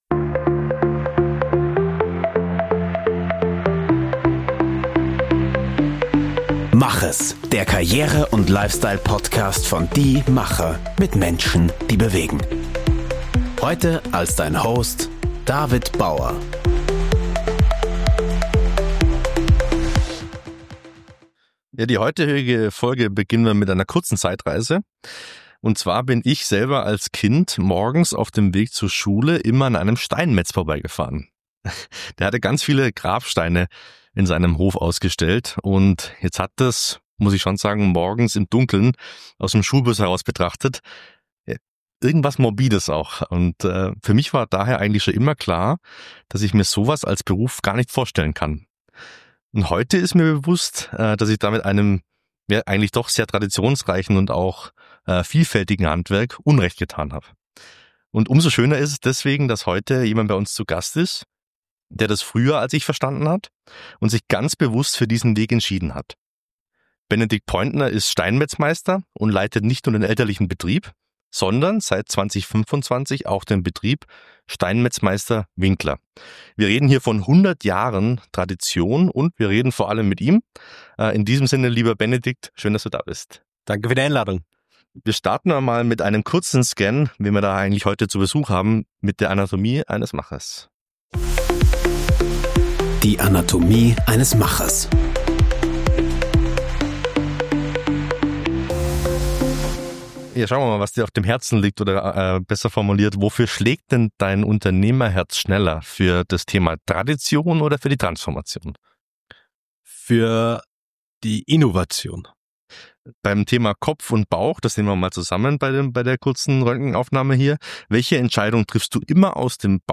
Im Interview lernen wir: